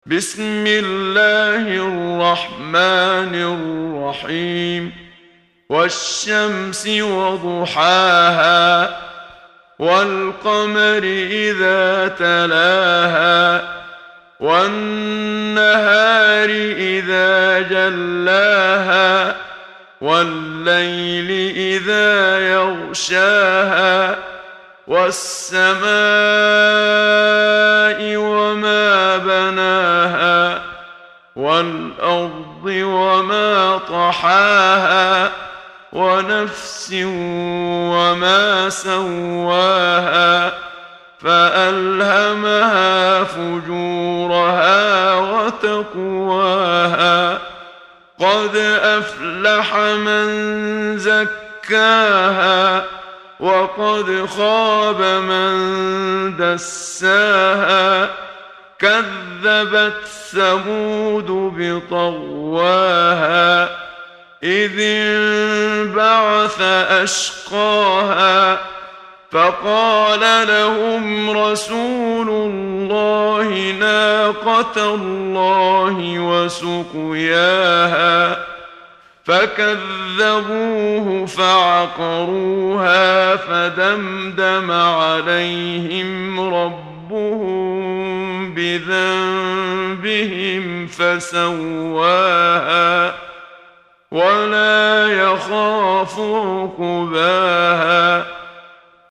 محمد صديق المنشاوي – ترتيل – الصفحة 8 – دعاة خير